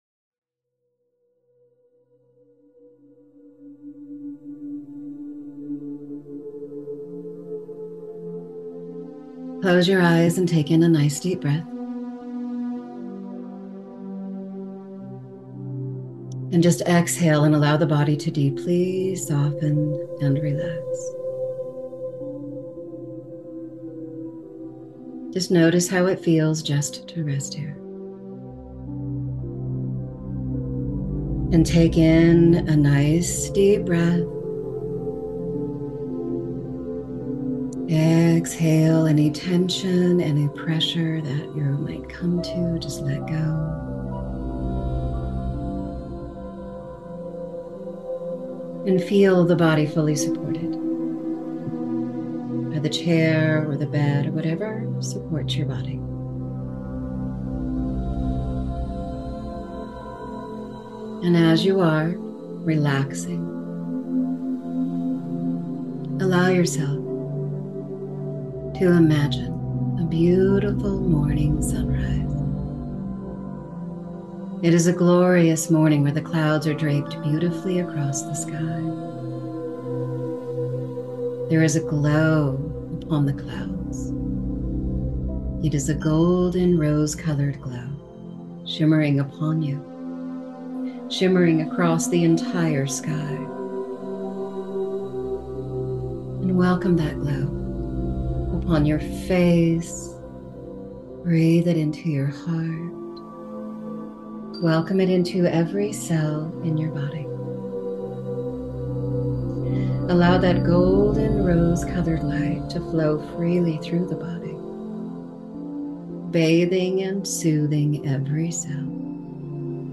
Free Guided Meditation